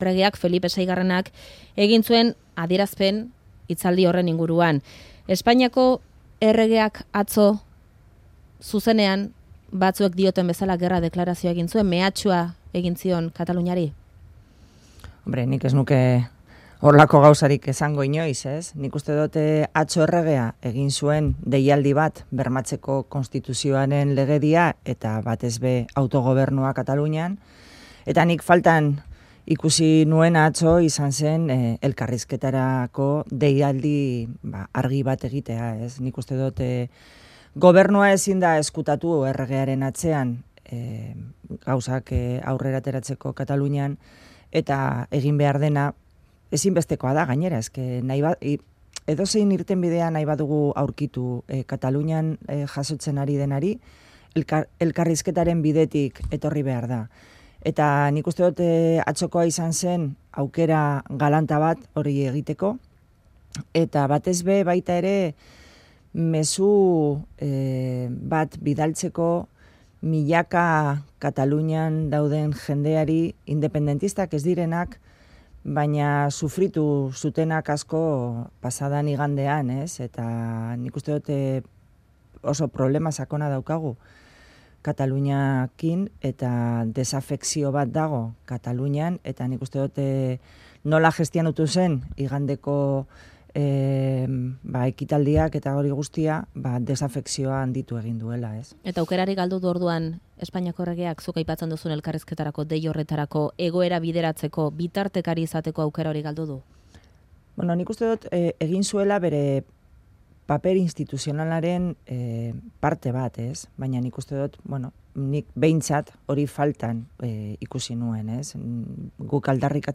Audioa: PSE-Euskadiko Ezkerrako idazkari nagusiak Faktorian kontatu digu Felipe VI Espainiako erregearen diskurtsoan elkarrizketarako deia bota zuen faltan bart.